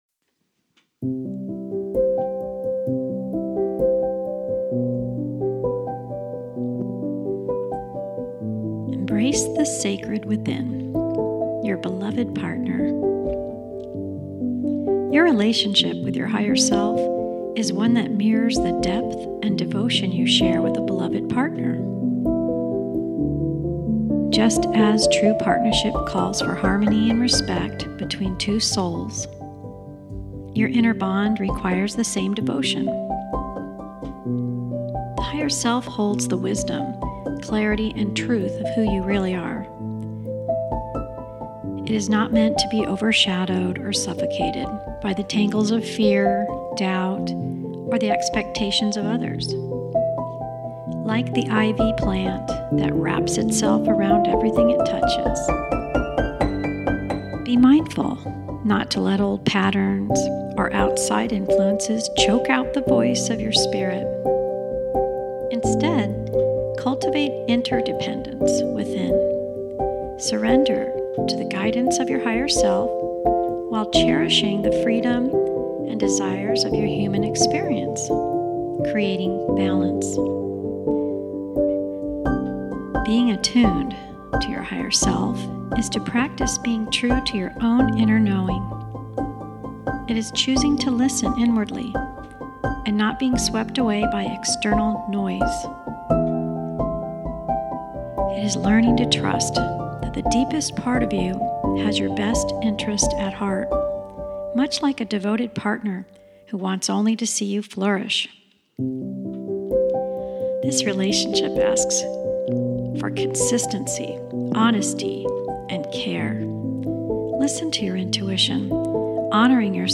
Music – Nature Music_for_Video Pixabay